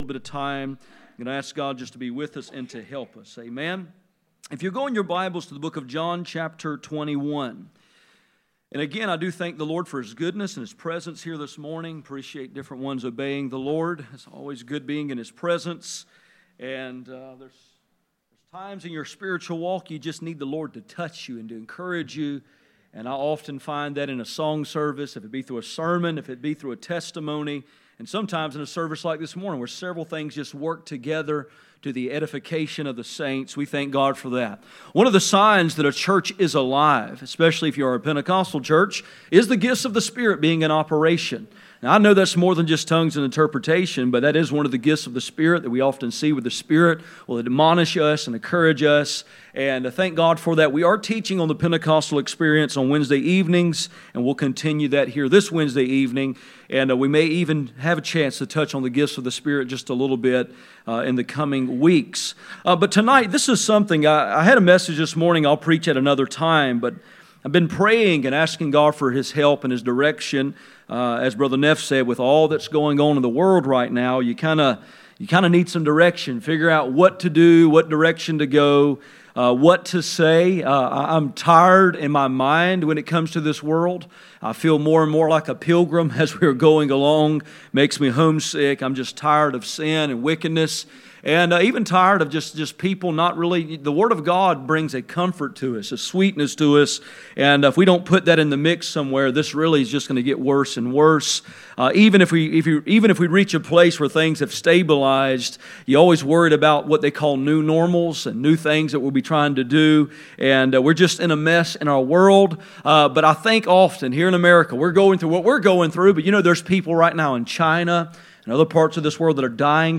None Passage: Philippians 2:12 Service Type: Sunday Evening %todo_render% « The baptism of the Holy Ghost The baptism of the Holy Ghost